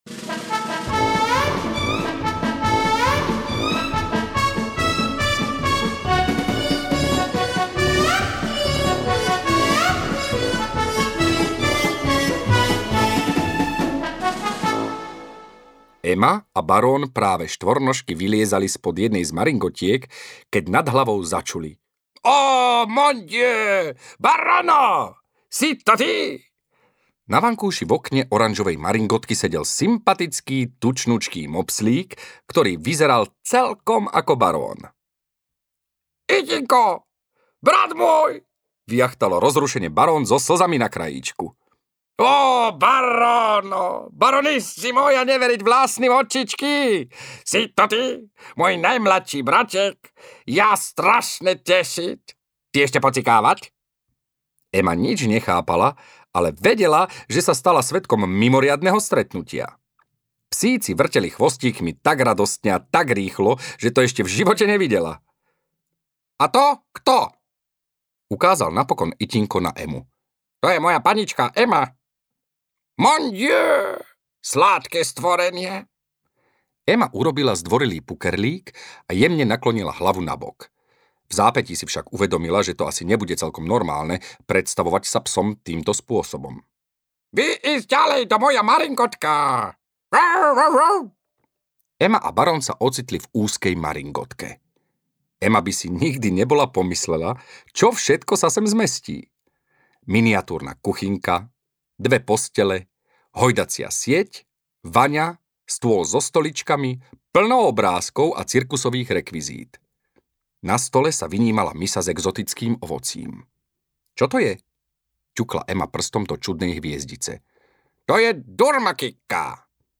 Ema a Barón v meste audiokniha
Ukázka z knihy
• InterpretĽuboš Kostelný